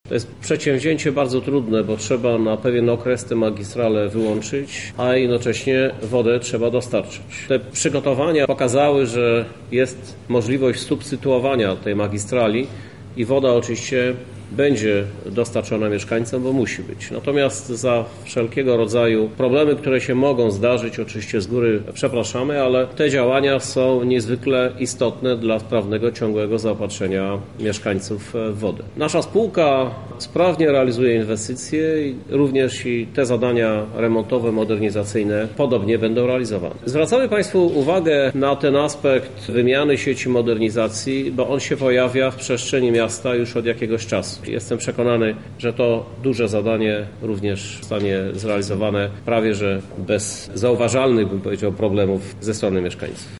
Ta magistrala daje zaopatrzenie w wodę około 40% mieszkańców – mówi Krzysztof Żuk, Prezydent Miasta Lublin: